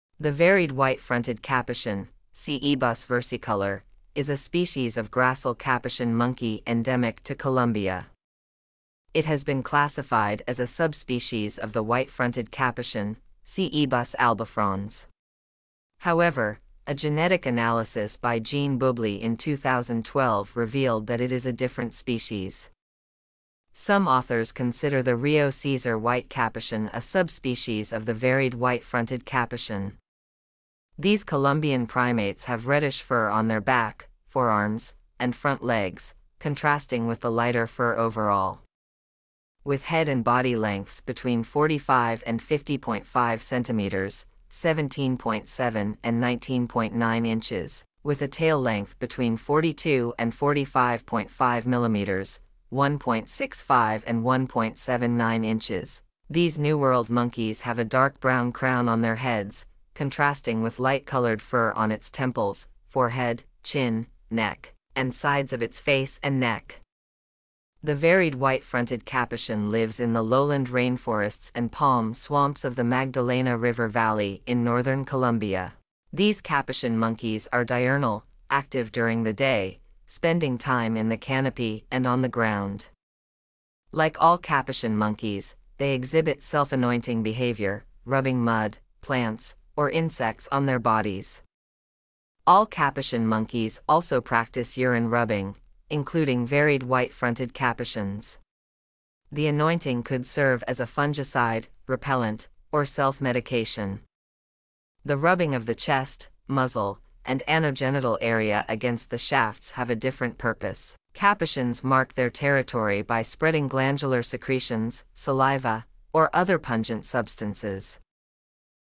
Varied White-fronted Capuchin
Varied-White-fronted-Capuchin.mp3